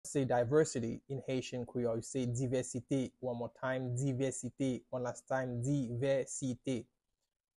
“Diversity” in Haitian Creole – “Divèsite” pronunciation by a Haitian Creole teacher
“Divèsite” Pronunciation in Haitian Creole by a native Haitian can be heard in the audio here or in the video below:
How-to-say-Diversity-in-Haitian-Creole-–-Divesite-pronunciation-by-a-Haitian-teacher.mp3